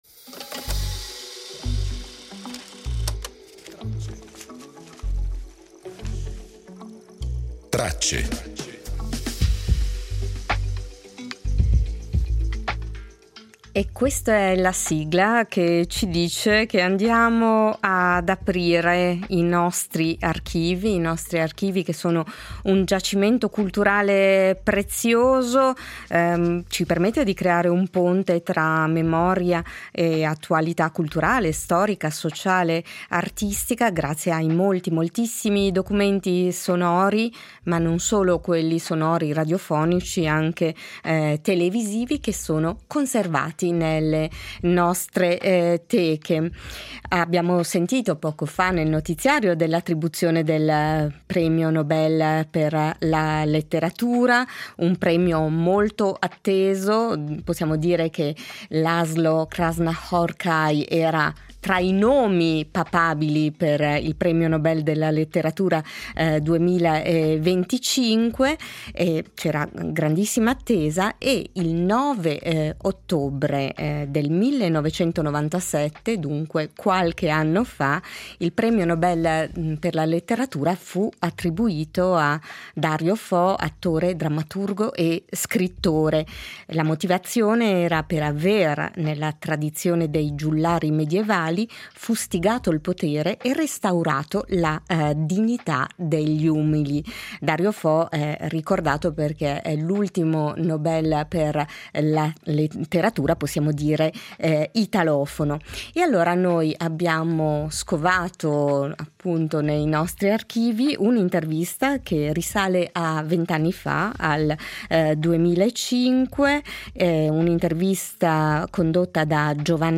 In Tracce estratti d’archivio sull’assegnazione del Premio Nobel per la Letteratura a Dario Fo, sui 30 anni in tv del cane Peo e sulla giornata mondiale della Posta.